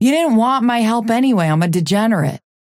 Vyper voice line - You didn't want my help anyway, I'm a degenerate.